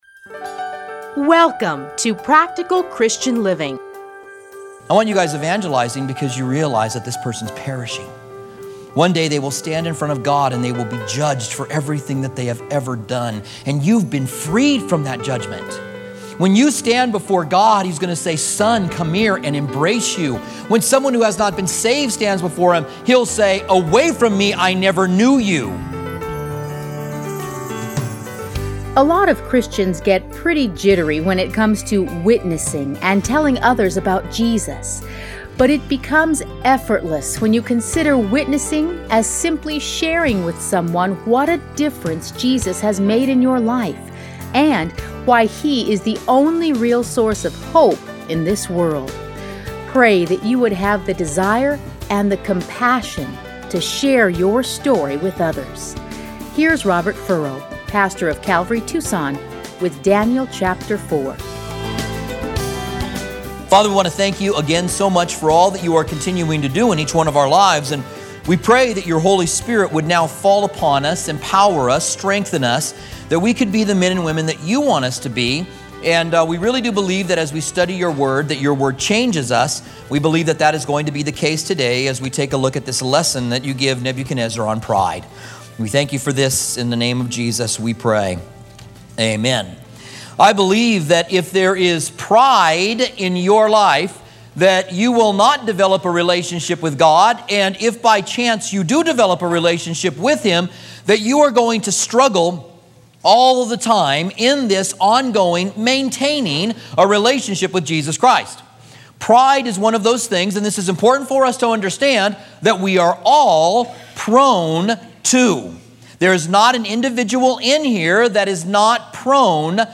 Listen here to his series in Daniel.